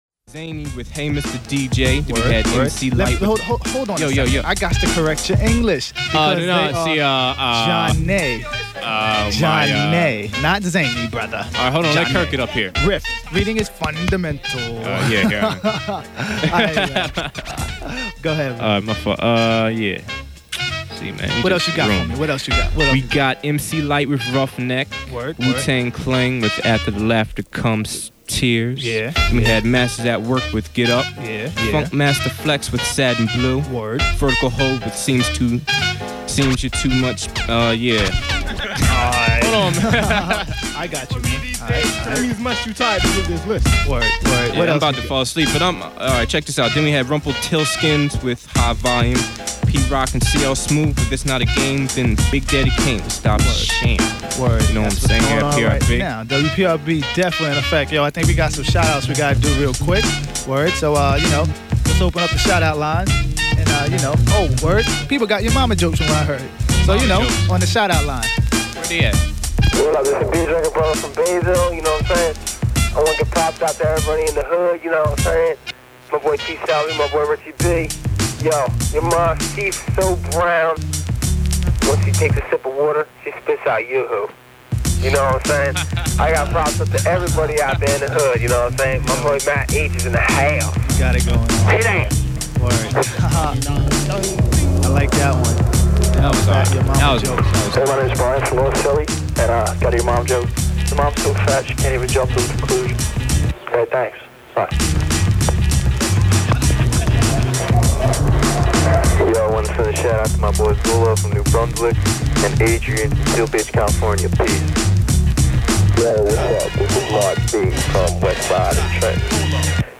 This is a second half tape, meaning “after midnight”, and also meaning lots of freestyles.